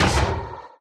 mob / irongolem / hit1.ogg
should be correct audio levels.